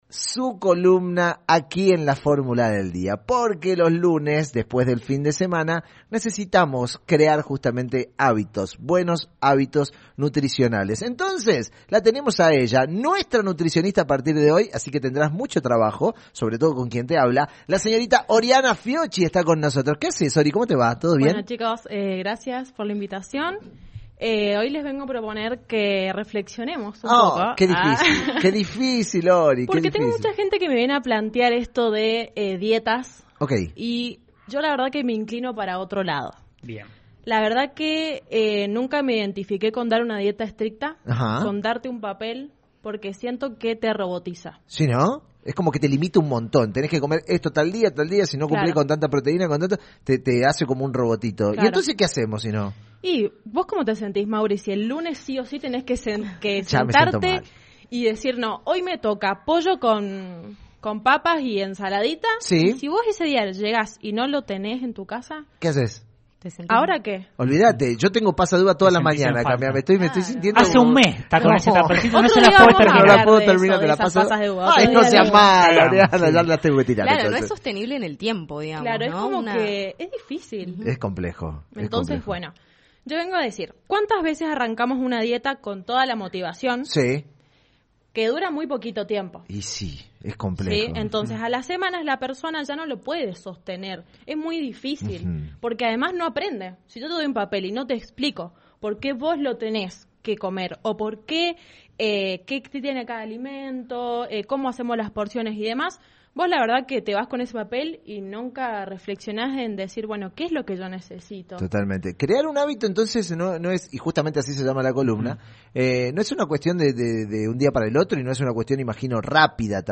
Columna “Creando hábitos” en LV18